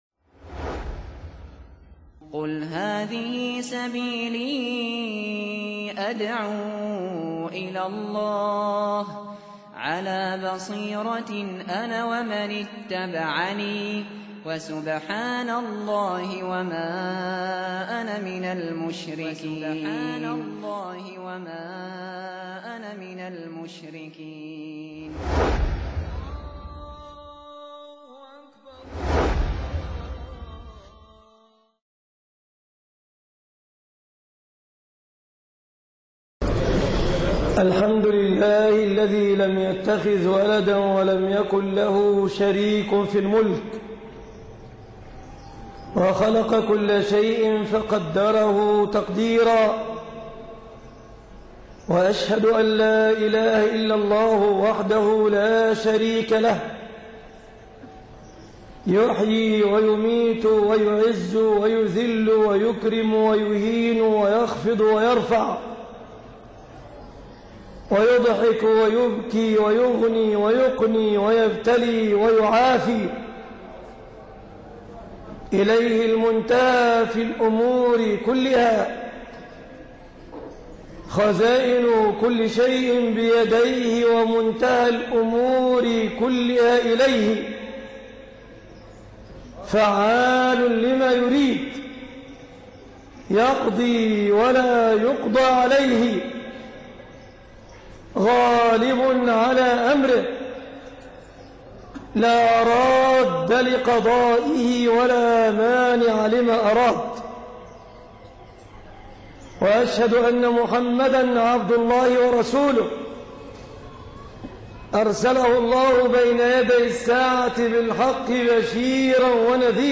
-خطب الجمعة